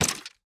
Drop Arrows A.wav